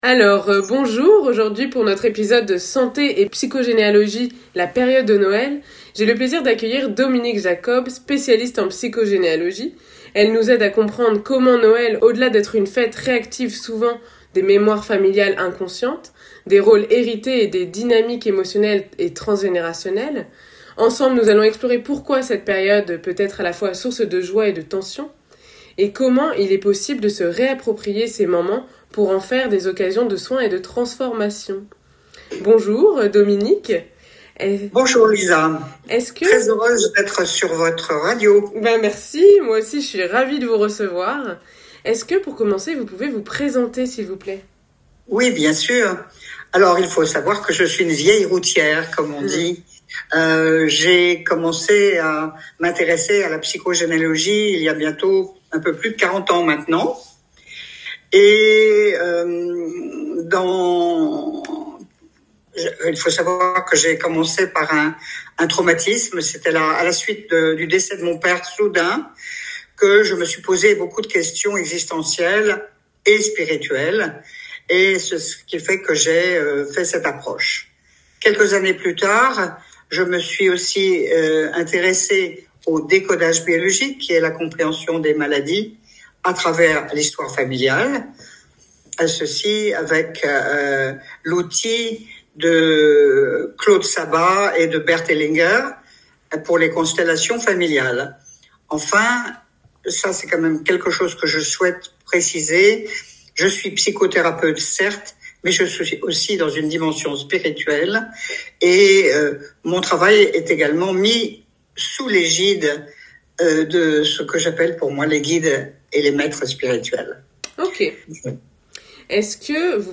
Interview exclusive